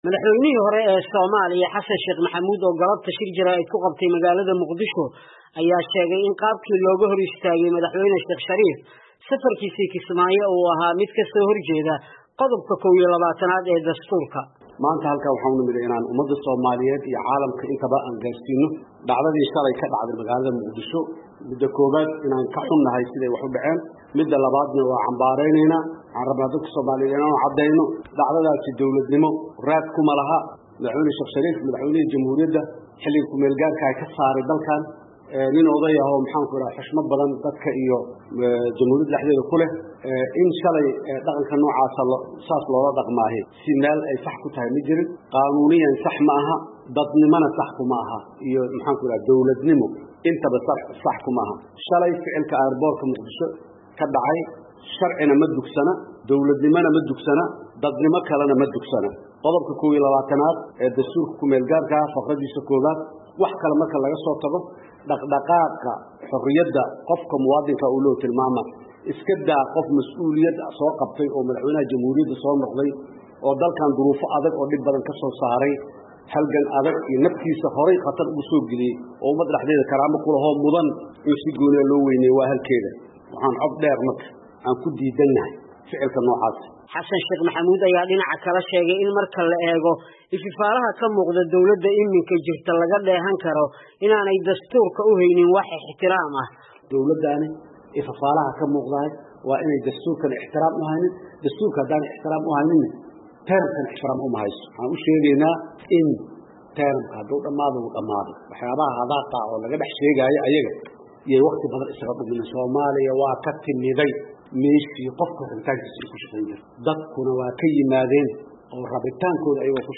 Madaxweynihii hore ee Soomaaliya Xasan Sheekh Maxamuud oo shir jaraa’id ku qabtay Muqdisho ayaa dhalleeceeyey go’aankii lagu hor-istaagay safar uu madaxweynihii hore ee dowladii KMG Soomaaliya Sheekh Shariif ku aadi lahaa magaalada Kismaayo.